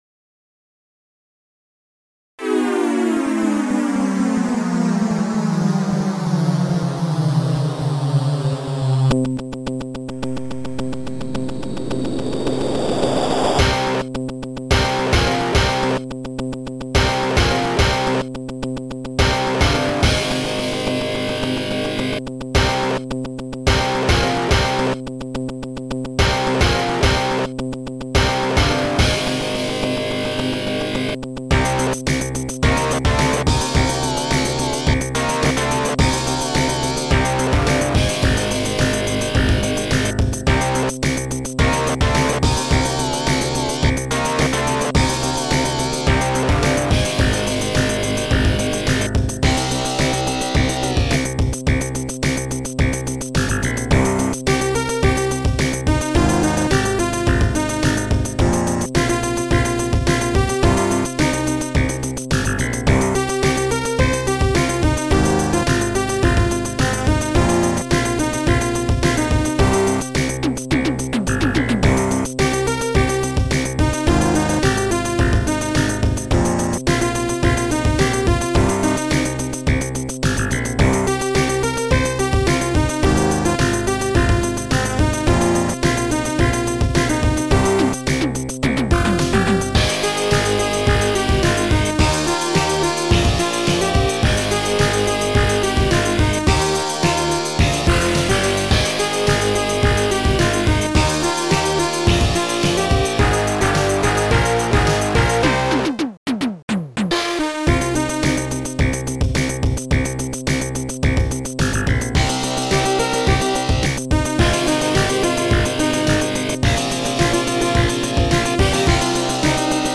Soundtracker 15 Samples